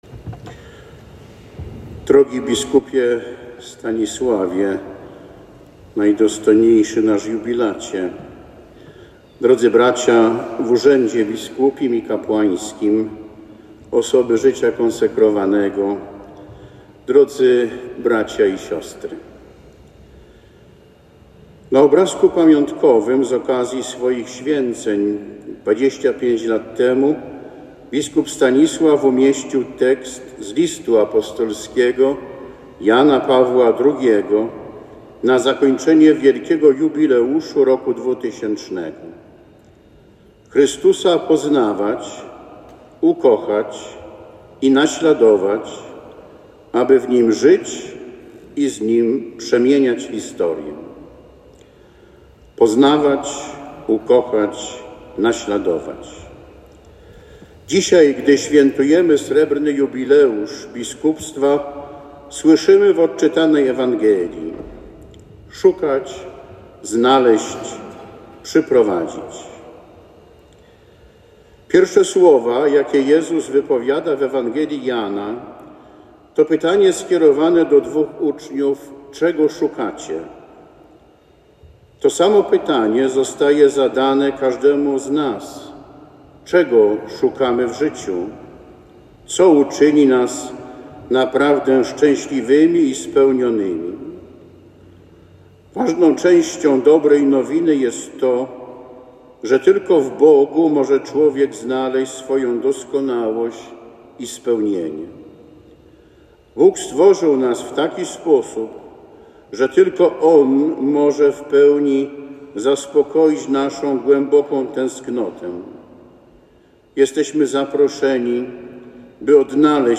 Bp Wętkowski - homilia na jubileuszu 25. rocznicy sakry biskupiej biskupa Stanisława Gębickiego, Włocławek Katedra